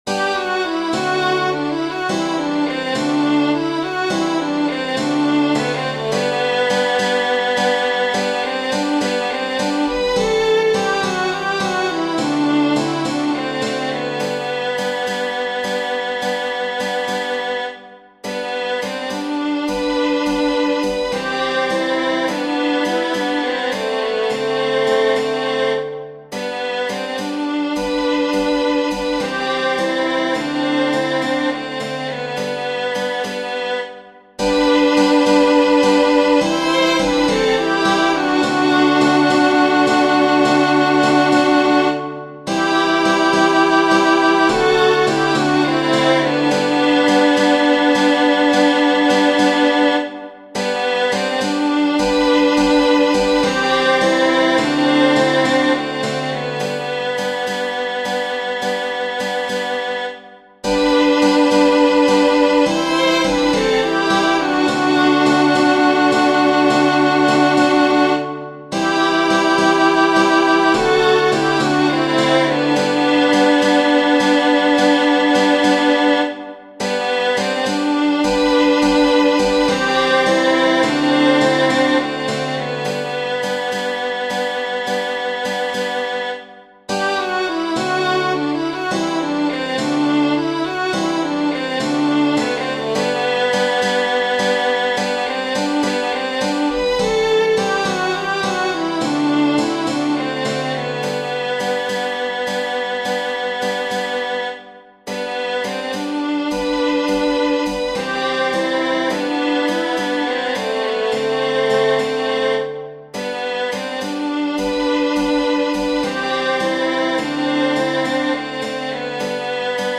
Tradizionale Genere: Folk Zhalna majka v' sebe plache, vnucite gi teshi, bol vo gradi lut ja vie, a nim im se smeshi.